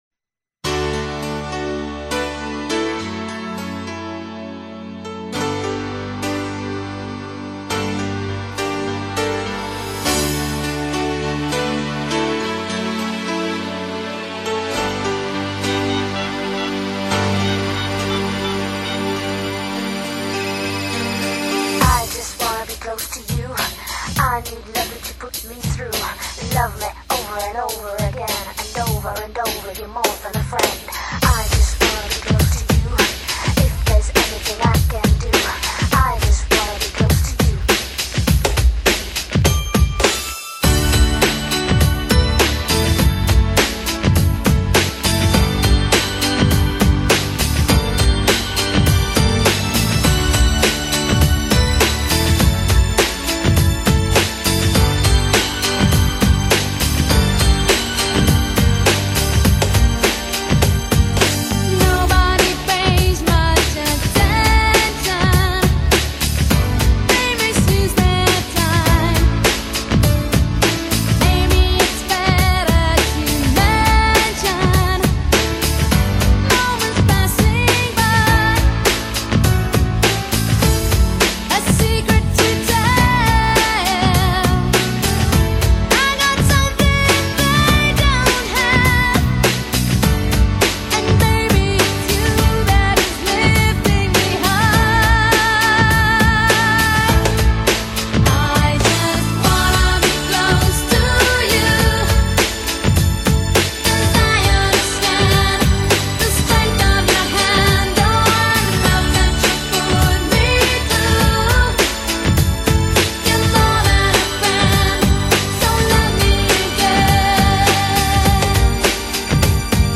风格：Euro-Pop, Dance-Pop